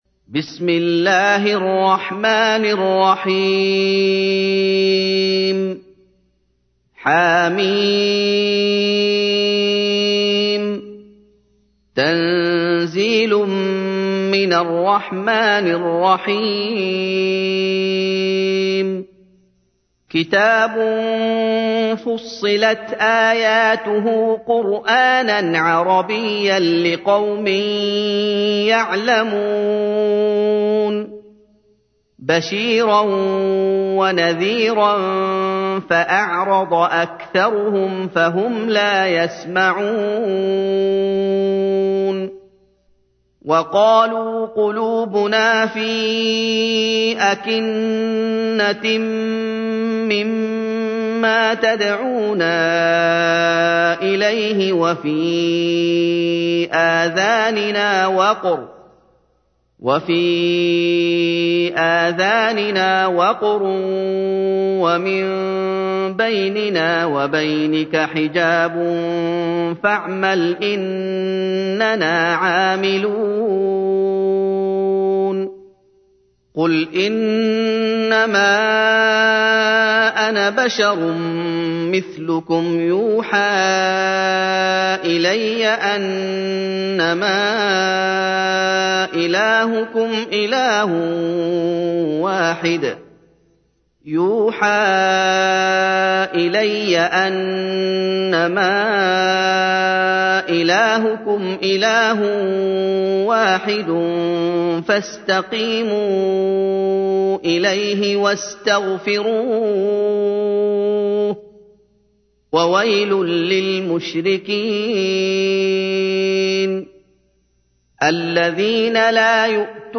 تحميل : 41. سورة فصلت / القارئ محمد أيوب / القرآن الكريم / موقع يا حسين